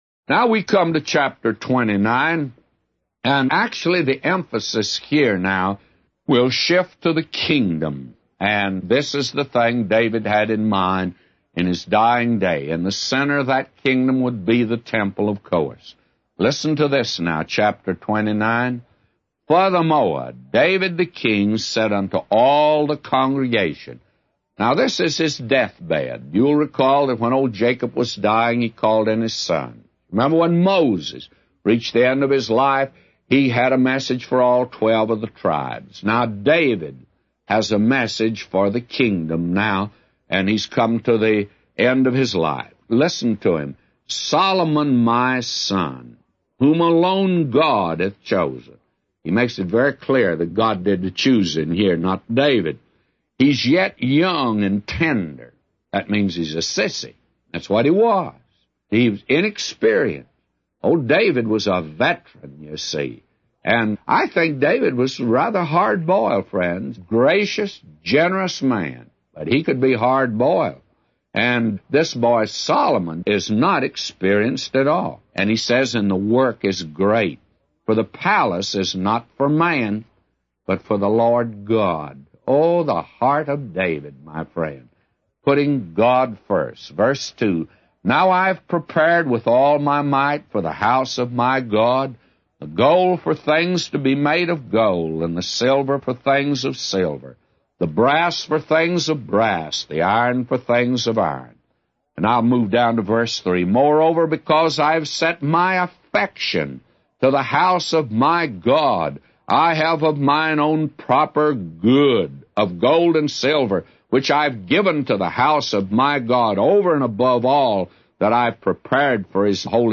A Commentary By J Vernon MCgee For 1 Chronicles 29:1-999